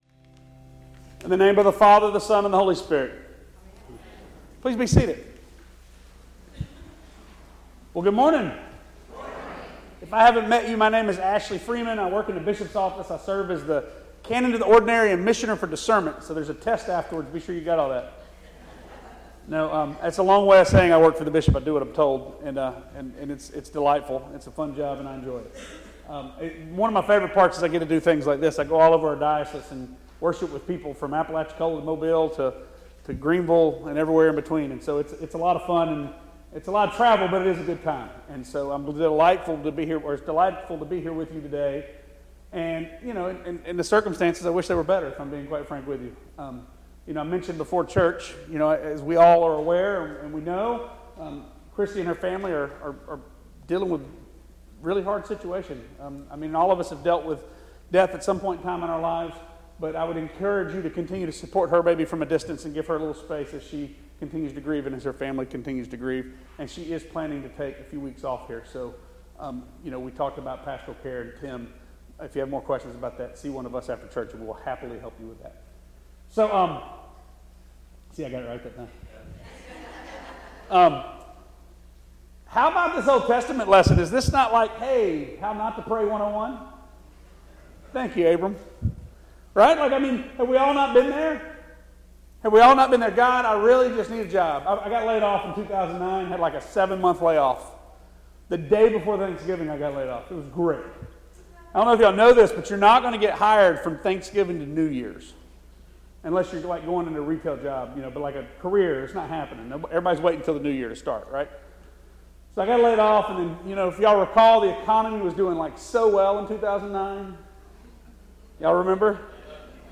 Sermon
at St. John’s Episcopal Church, Pensacola, Florida.